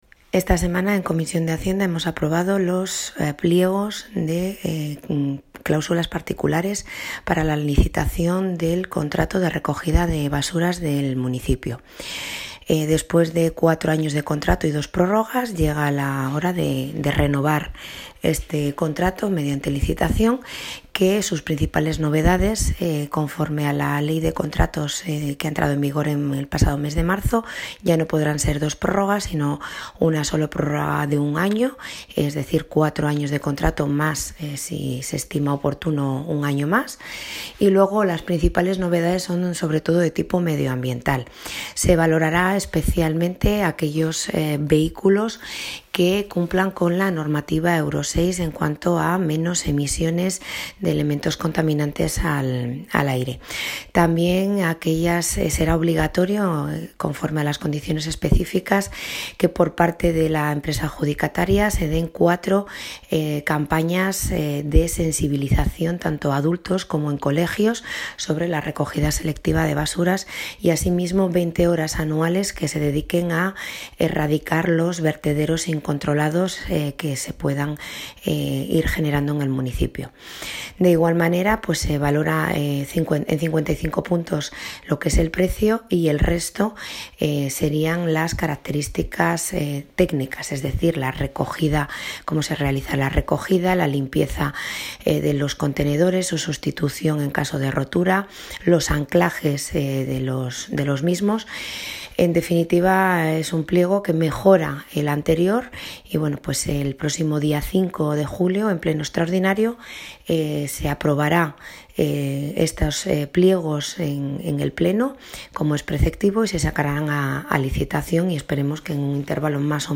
Alcaldesa-sobre-contrato-de-recogida-de-basuras.mp3